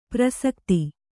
♪ prasakti